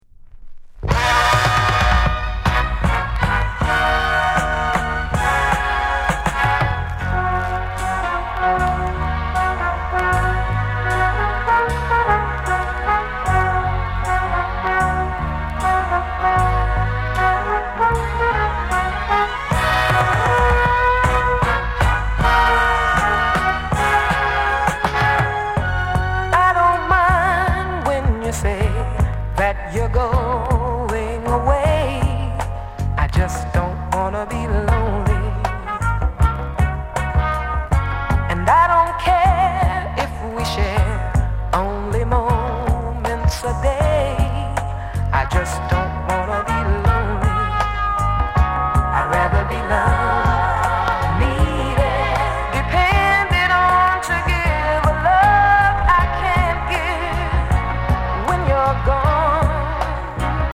SOUND CONDITION VG��
JAMAICAN SOUL